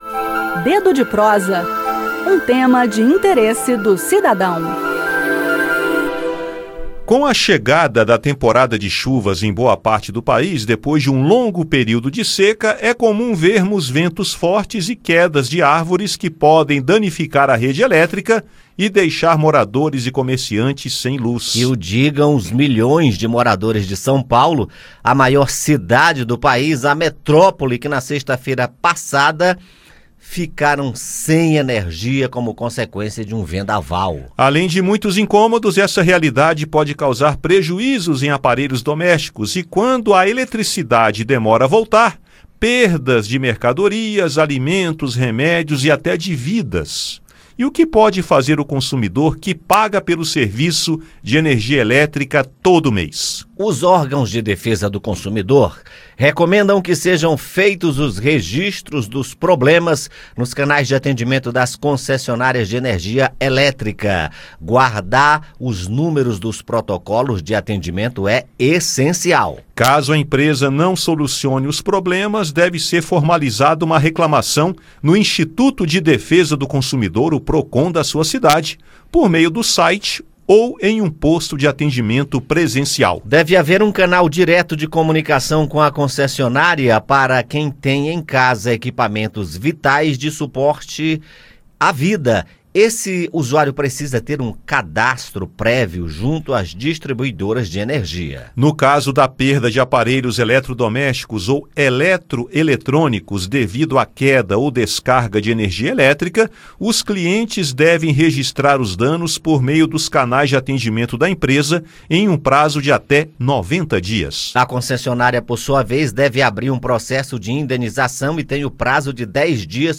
Além do incômodo, o apagão causou prejuízos aos consumidores e comerciantes. Ouça no bate-papo o que pode ser feito no caso de perda de aparelhos eletrodomésticos ou eletroeletrônicos, o que diz a legislação sobre o direito à indenização e como usar os canais de atendimento das concessionárias de energia elétrica para o reclame.